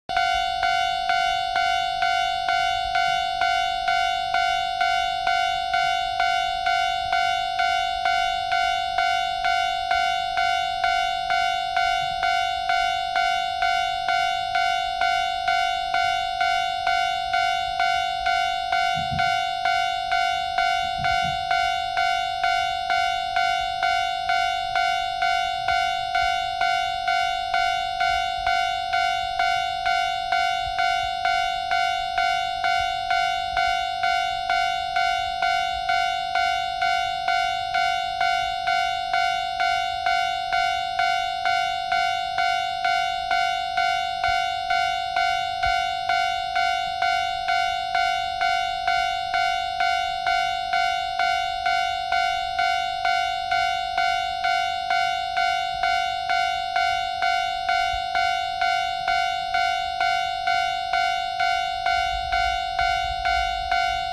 ●踏切警報音発生装置の音（あの「カンカン」という音です。
東邦電機工業高音タイプ　NEW!
fumikiri4.wma